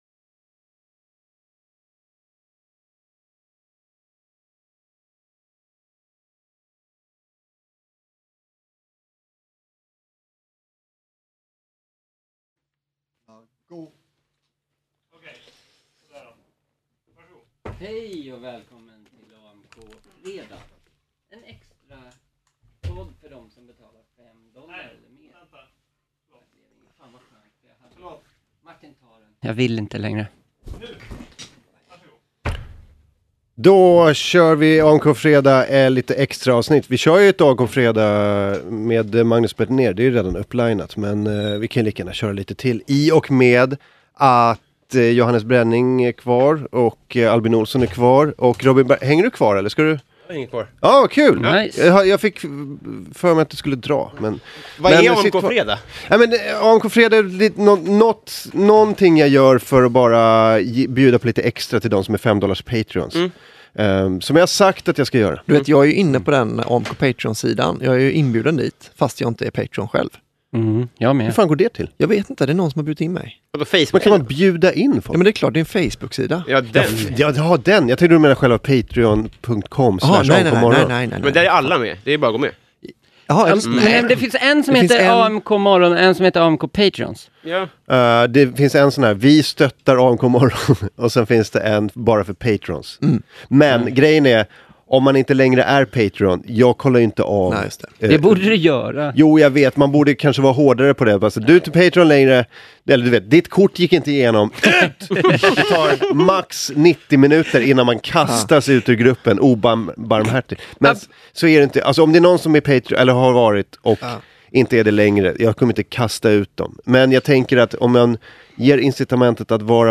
Är det bara jag som gör något fel eller är det dryga 51 minuter tystnad vi får oss till livs?